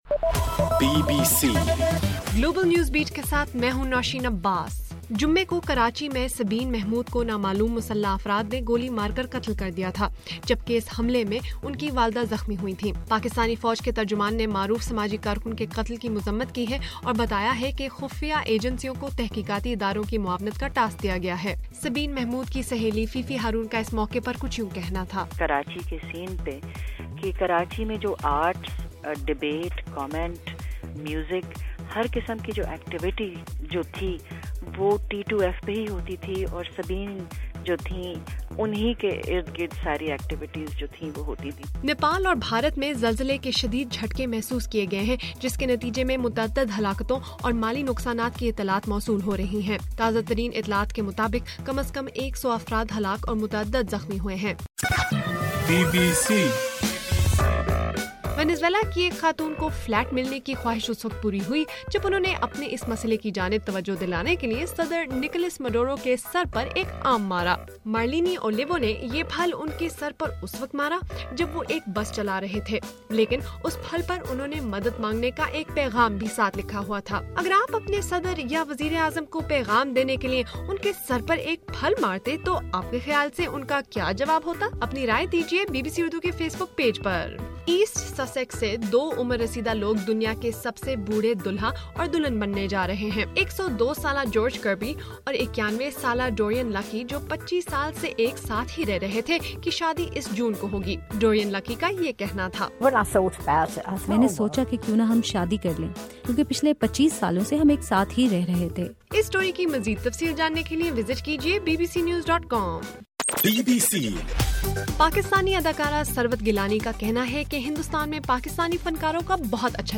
اپریل 25: رات 8 بجے کا گلوبل نیوز بیٹ بُلیٹن